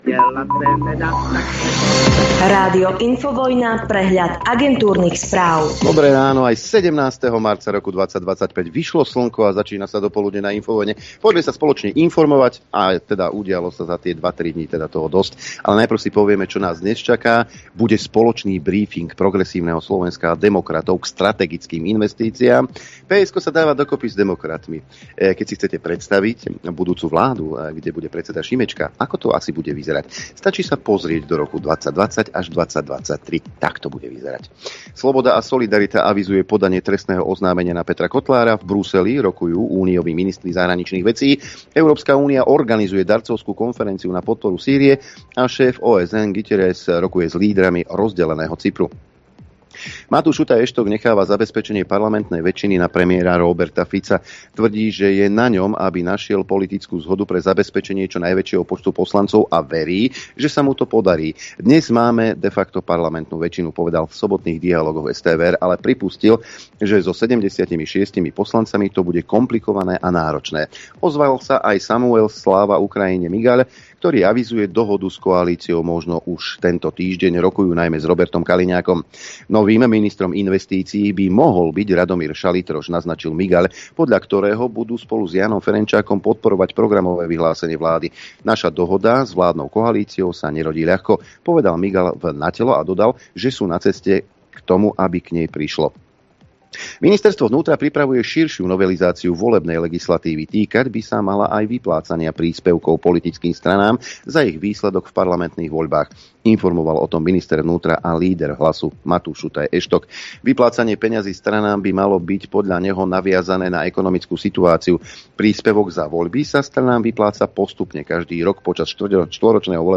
repeat continue pause play stop mute max volume Živé vysielanie 1.